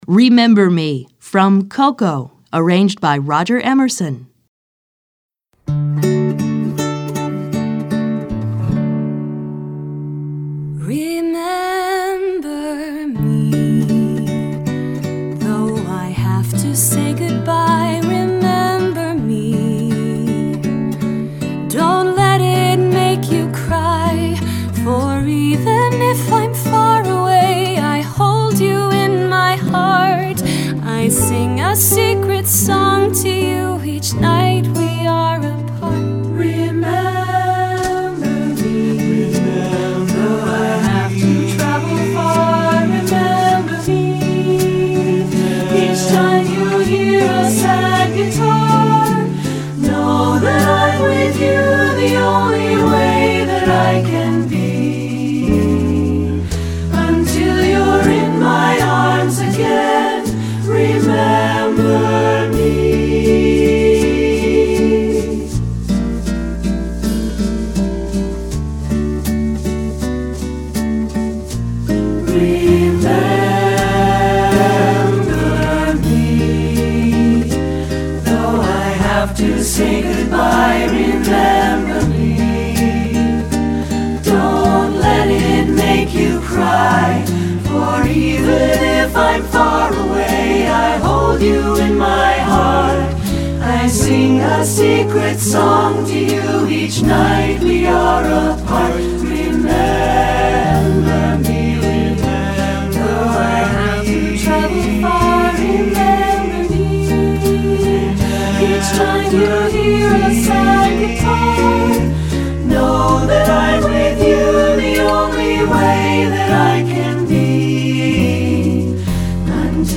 Recueil pour Chant/vocal/choeur - 3 Chant Mixtes